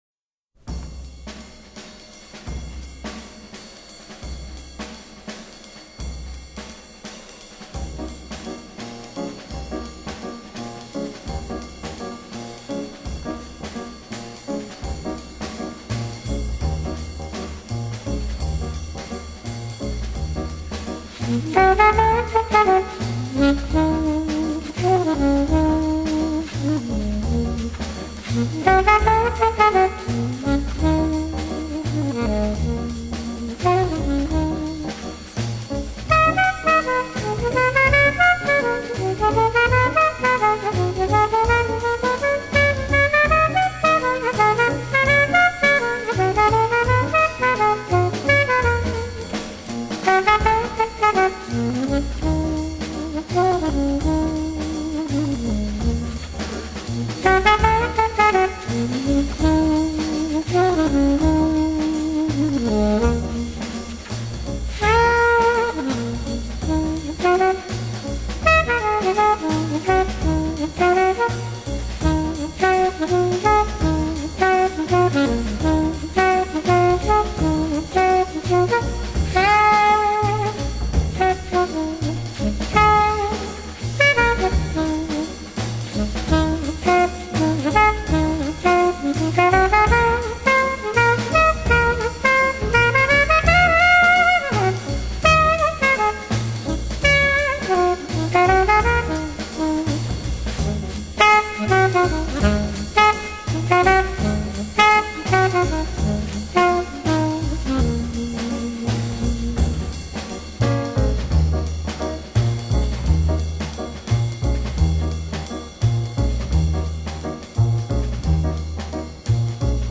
这张专辑中八分之九拍、四分之五拍、四分之六拍等诸多非常规爵士乐节拍也有出现，